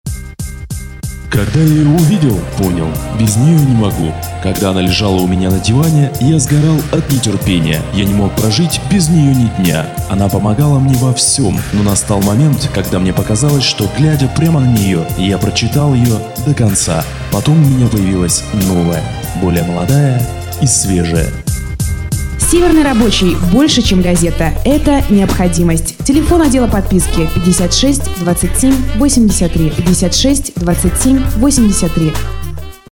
Радиоролик городской газеты (сценарий) Категория: Копирайтинг